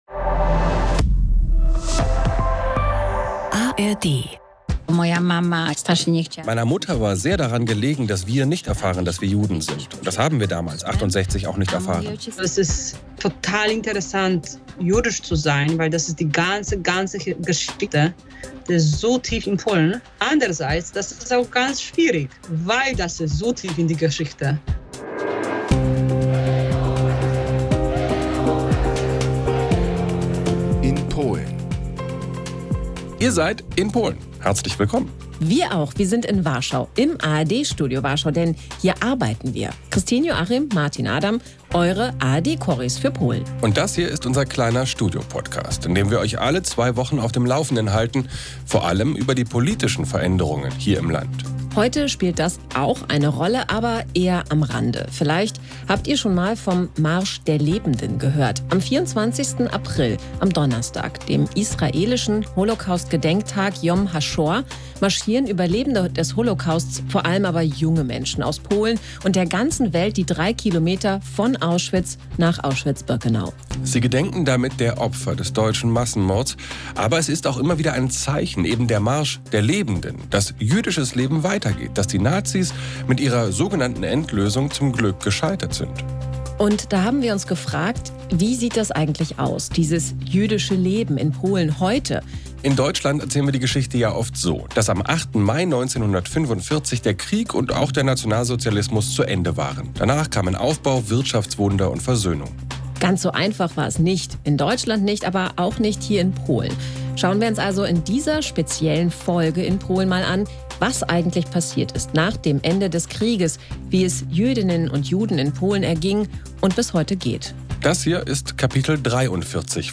"In Polen" ist der Podcast aus dem ARD-Studio in Warschau